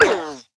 Minecraft Version Minecraft Version 25w18a Latest Release | Latest Snapshot 25w18a / assets / minecraft / sounds / mob / armadillo / hurt5.ogg Compare With Compare With Latest Release | Latest Snapshot
hurt5.ogg